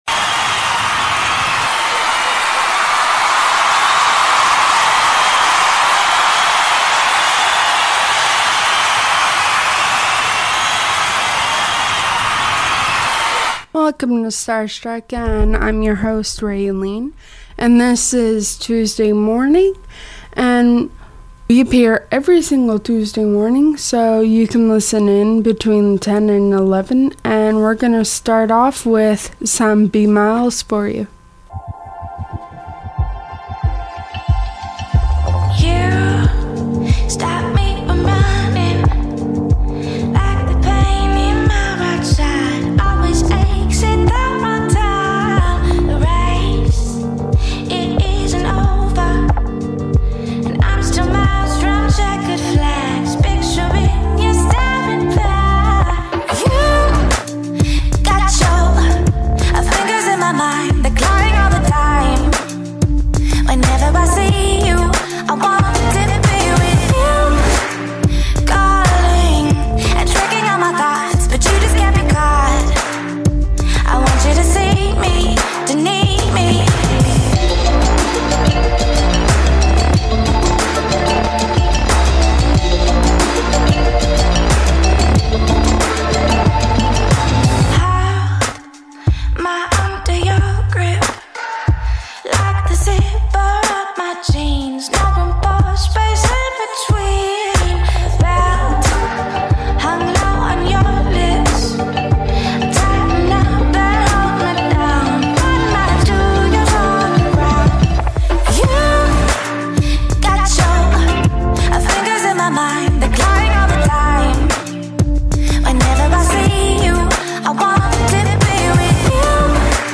An Open Format Music Show - Pop, Acoustic, Alternative Rock,as well as Local/Canadian artists